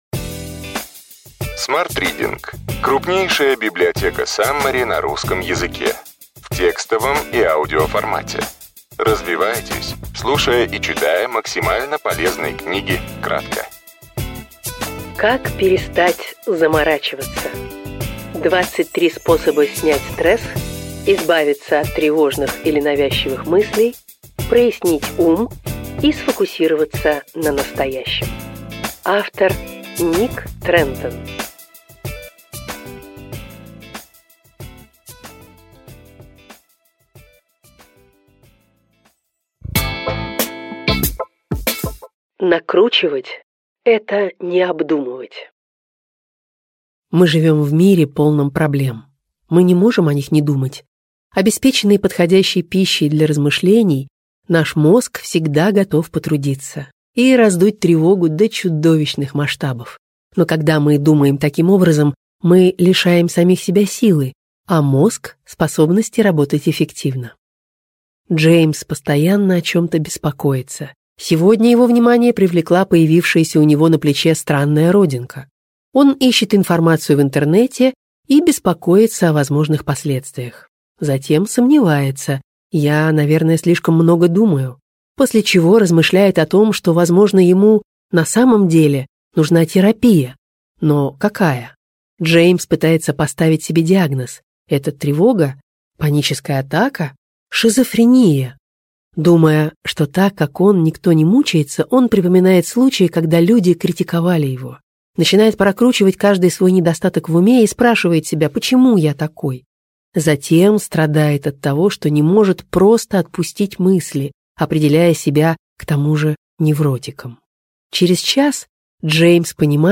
Аудиокнига Ключевые идеи книги: Как перестать заморачиваться. 23 способа снять стресс, избавиться от тревожных или навязчивых мыслей, прояснить ум и сфокусироваться на настоящем.